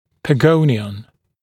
[pə’gəunɪən][пэ’гоуниэн]погонион (Pog) (цефалометрический ориентир)